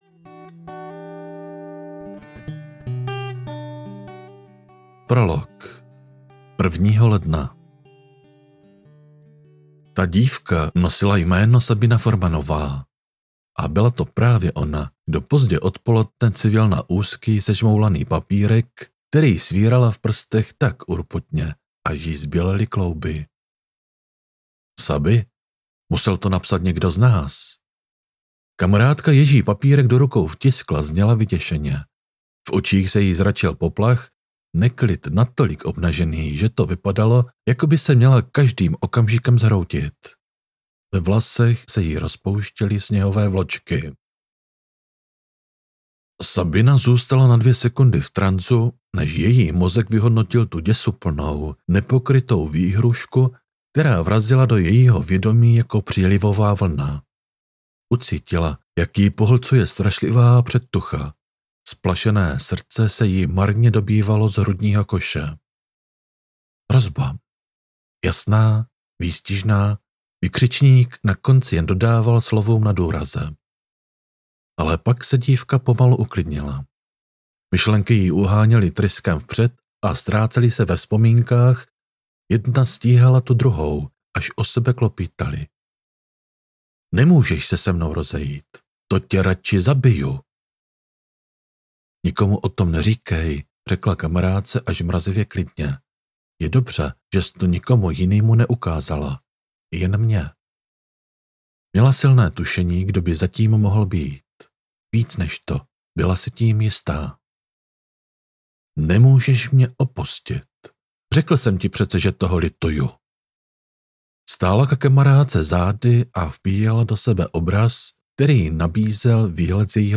Já, Smrt audiokniha
Ukázka z knihy